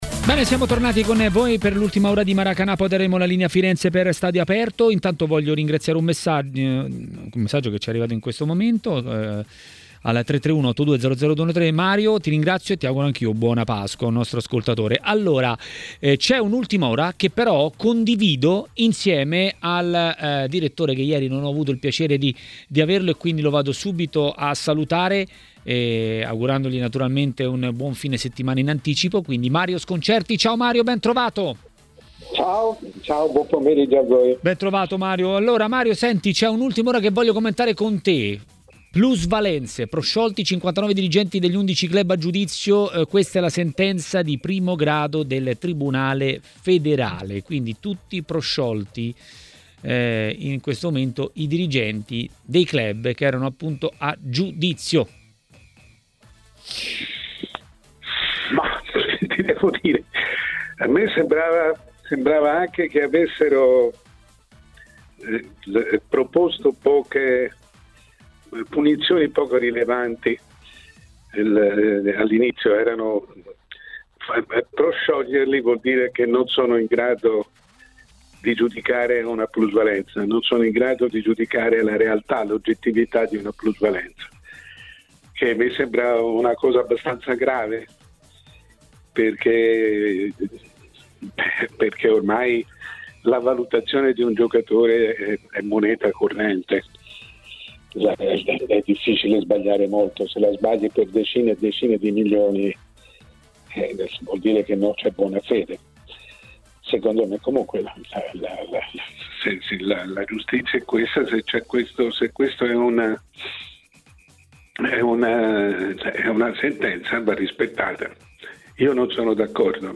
A TMW Radio, durante Maracanà, è arrivato il momento del direttore Mario Sconcerti.